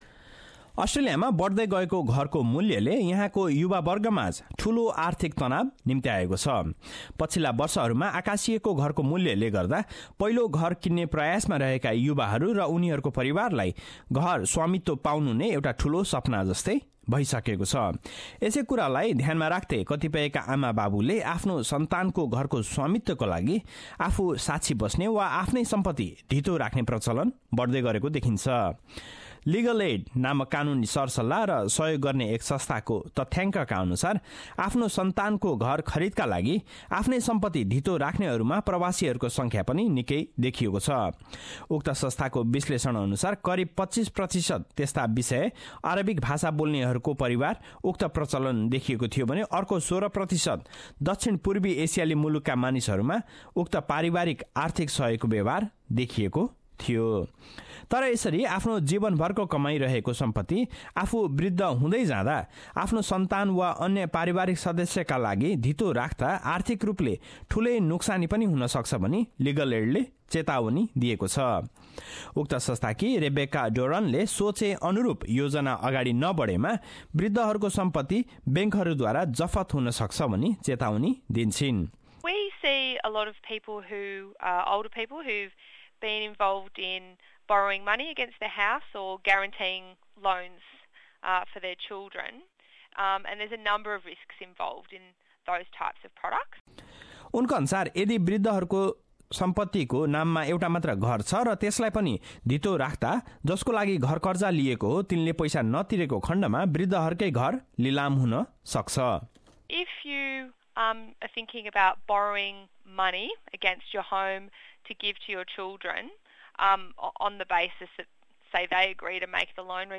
रिपोर्ट